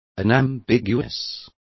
Complete with pronunciation of the translation of unambiguous.